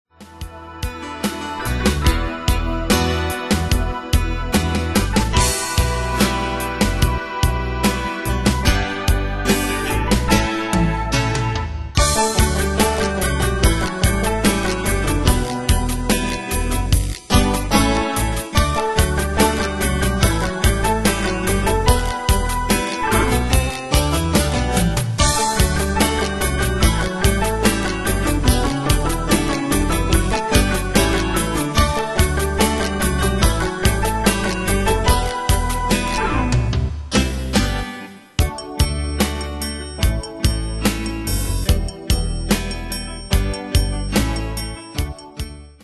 Demos Midi Audio
Style: PopFranco Année/Year: 1994 Tempo: 73 Durée/Time: 3.50
Danse/Dance: Ballade Cat Id.